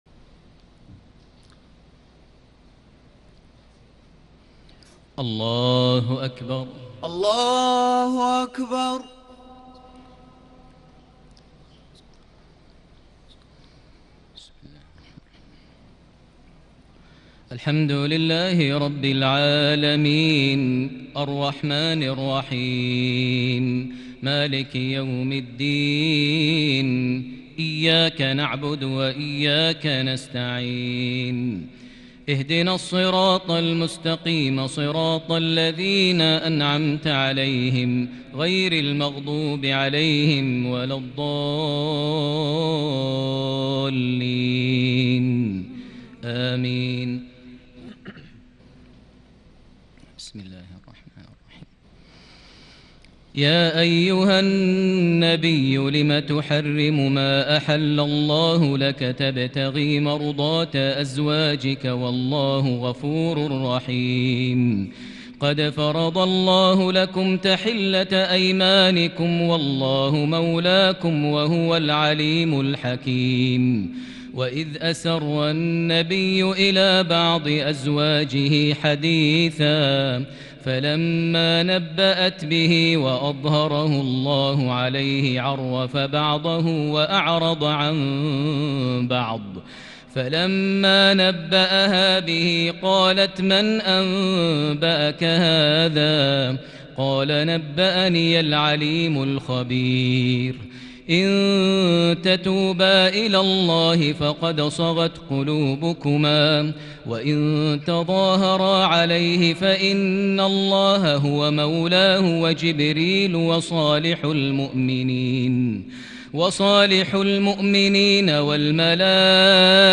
تهجد ليلة 28 رمضان 1441هـ سورة التحريم حتى سورة القلم | Tahajjud prayer The 28th night of Ramadan 1441H Surah At-Tahrim to me al-Qalam > تراويح الحرم المكي عام 1441 🕋 > التراويح - تلاوات الحرمين